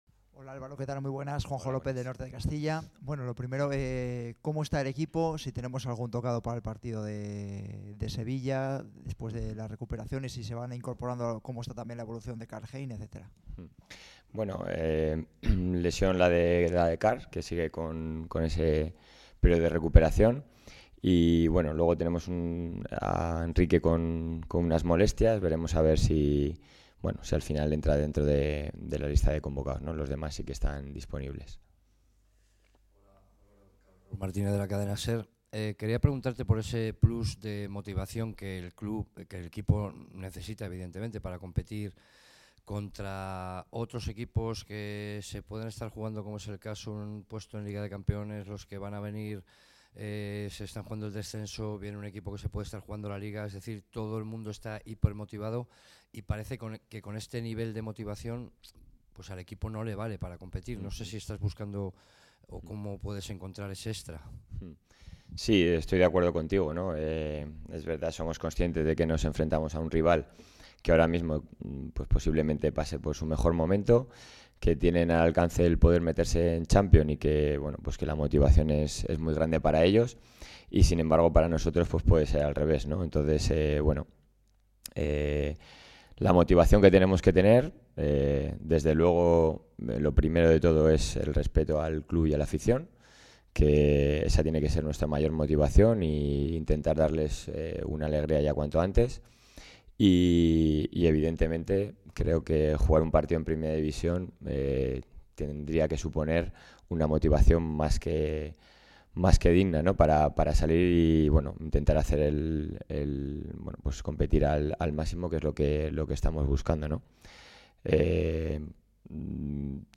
Álvaro Rubio fue contundente en rueda de prensa, en este 23 de abril de honrar las raíces castellanas, de sentido de pertenencia, en la rueda de prensa previa al duelo ante el Real Betis en un partido, este jueves, que podría suponer el descenso matemático.
Esta reflexión fue el pilar central de una comparecencia en la que trató de explicar la situación del equipo esta temporada.